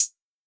Closed Hats
JJHiHat (4).wav